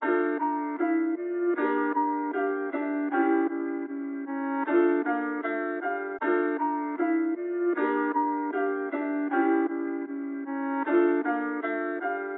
Tag: 155 bpm Trap Loops Piano Loops 2.08 MB wav Key : A FL Studio